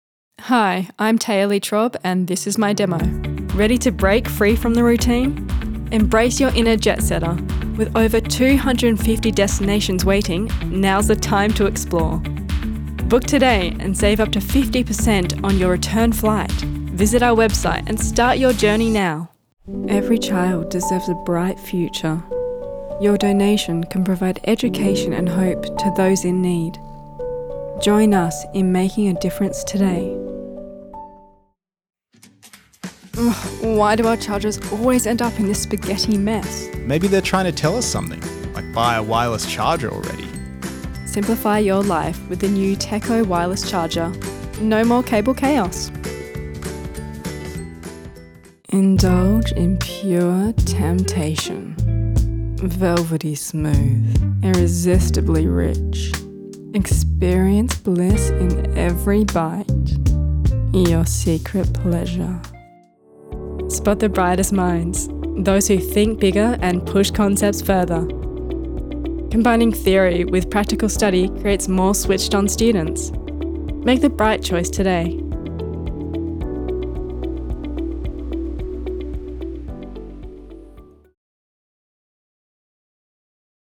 Voice Reel Demo